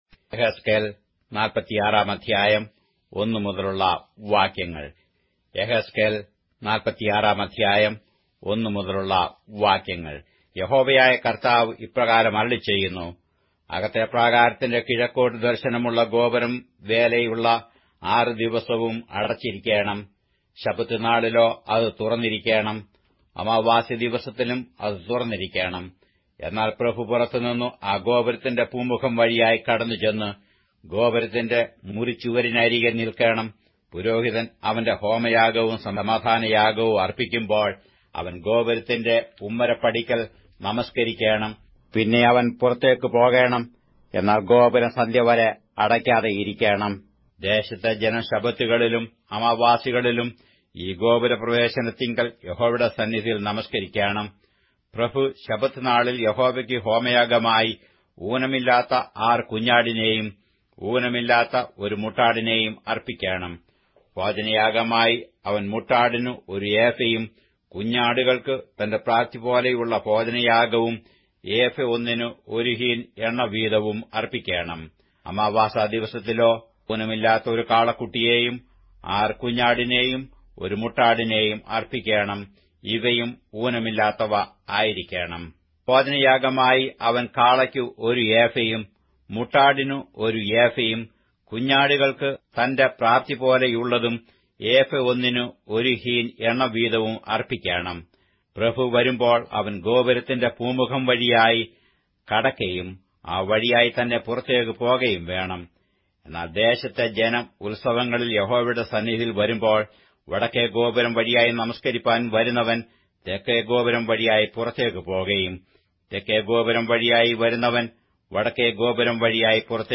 Malayalam Audio Bible - Ezekiel 43 in Pav bible version